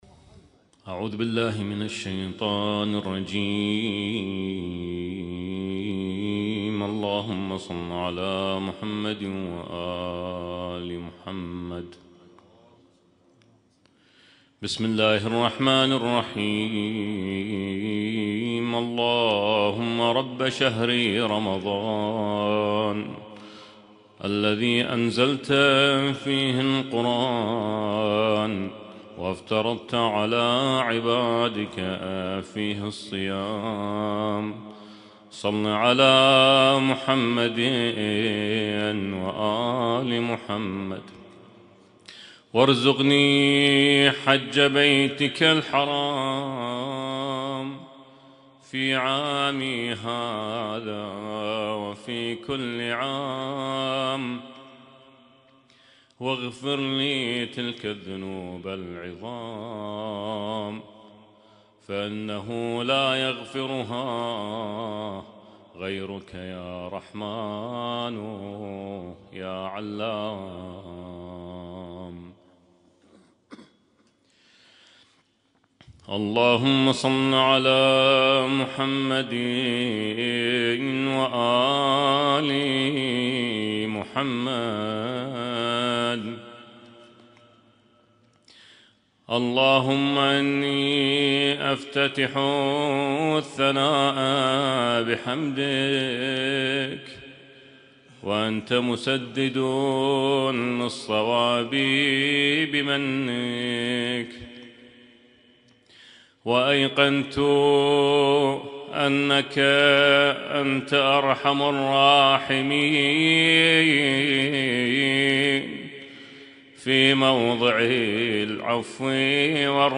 اسم التصنيف: المـكتبة الصــوتيه >> الادعية >> دعاء الافتتاح